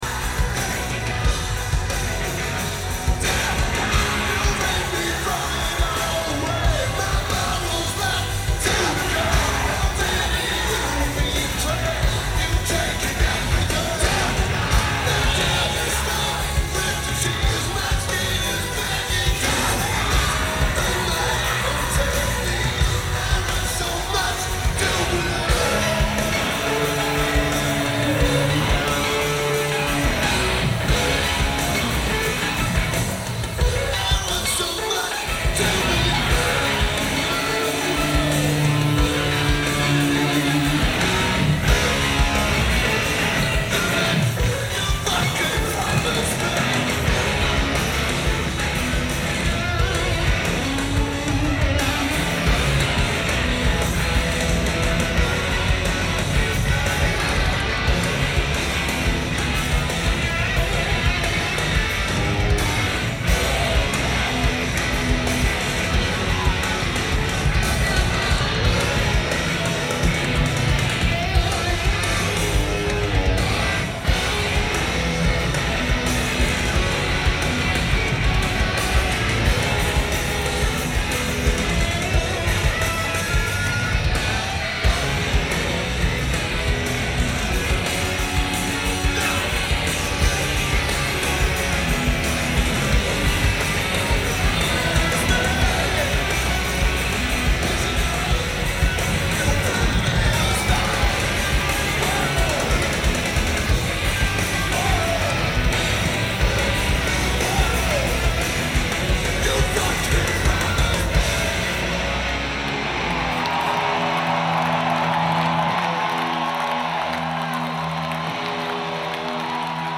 Grand Rapids, MI United States
Lineage: Audio - AUD (DPA 4060 + BB + Sony TCD-D8)
So, the recording skips for about half a second.